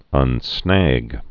(ŭn-snăg)